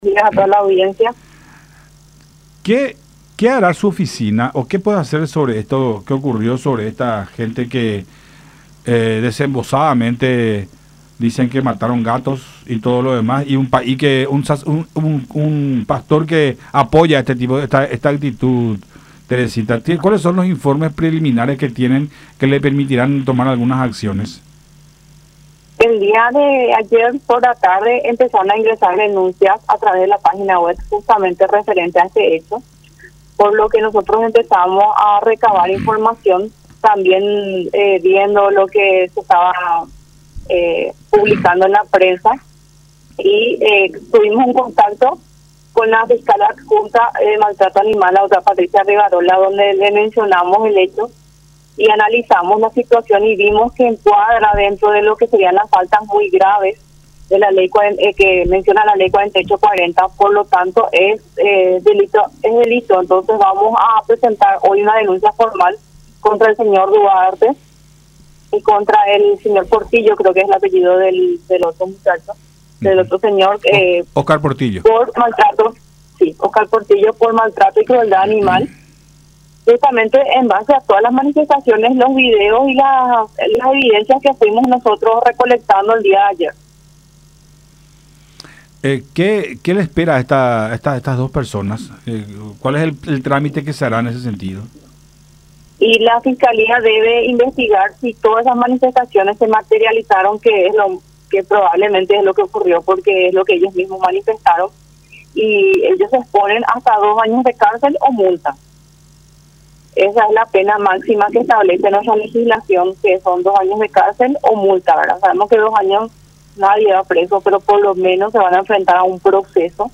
en conversación con Nuestra Mañana por Unión TV y radio La Unión.